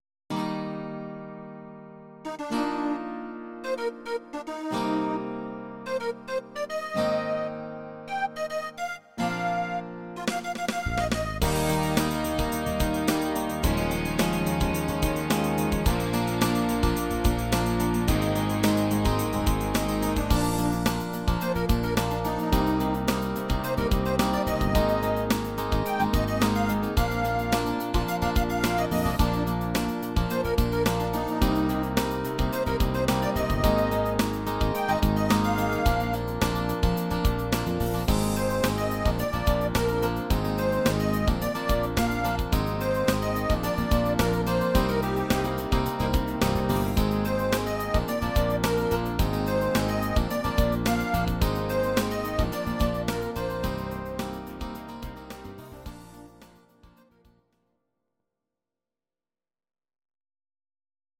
Audio Recordings based on Midi-files
cover